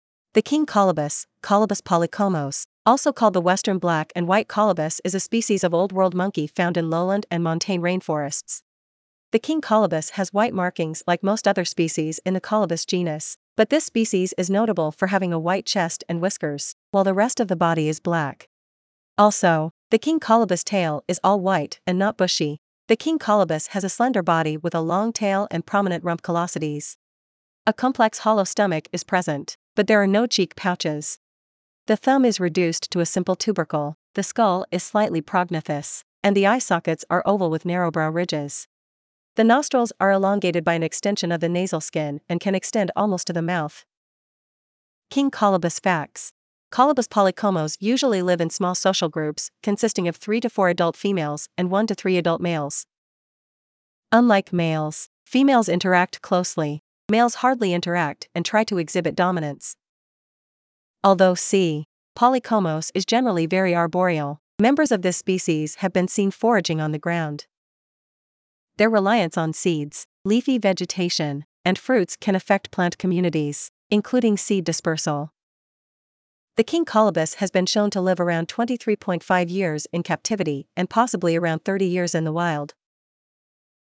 King-Colobus.mp3